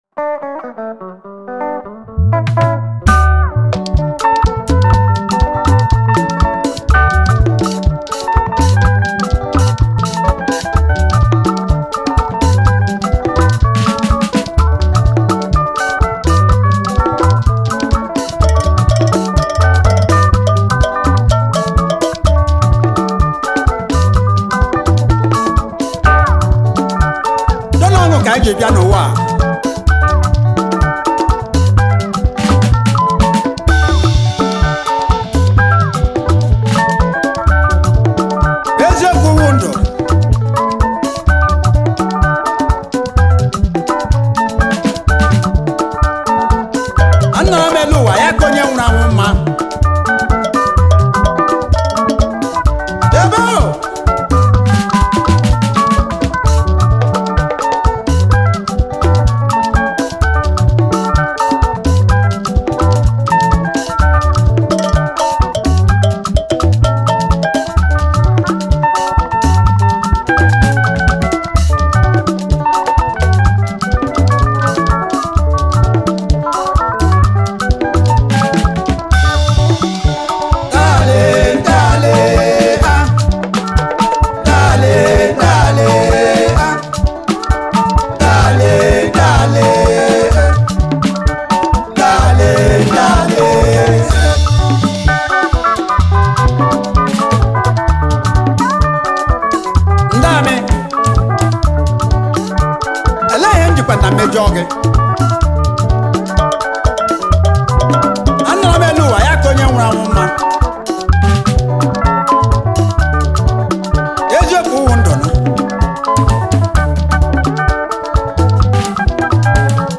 October 14, 2024 admin Highlife Music, Music 0
highlife single